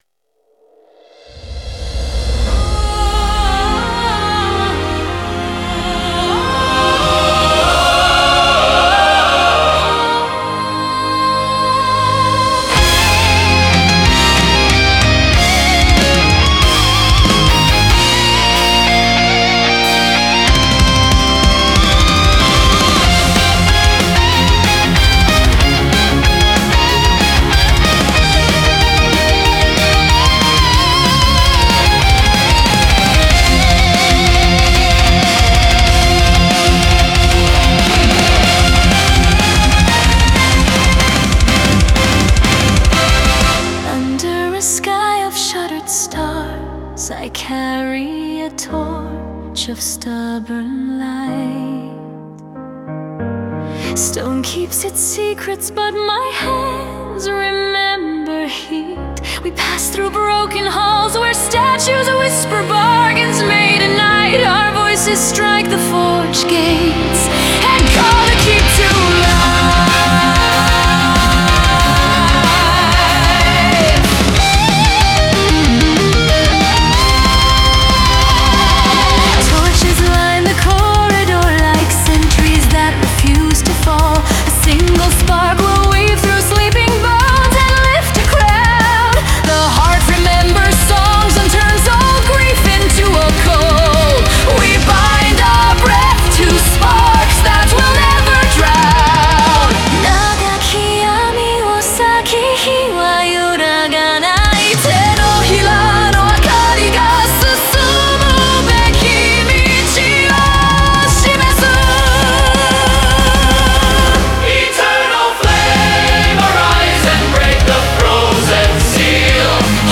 Symphonic Metal
Tempo around 190 BPM.